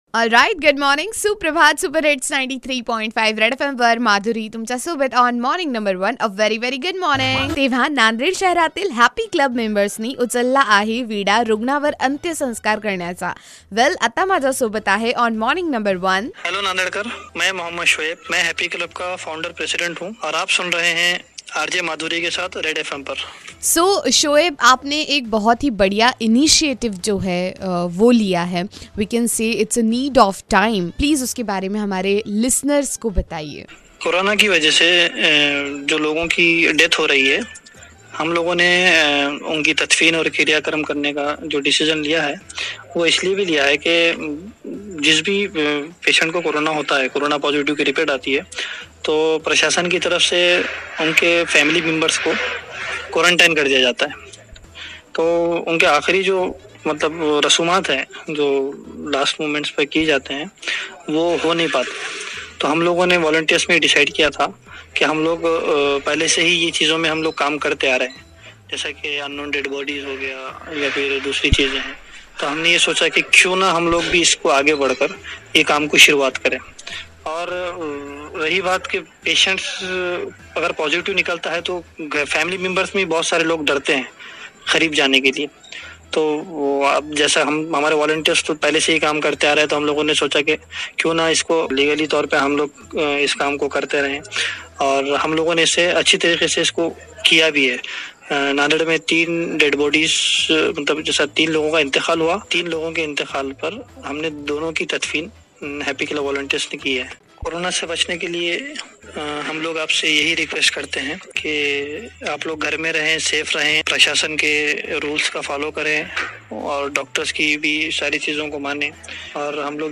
IN CONVERSATION WITH MEMBER OF HAPPY CLUB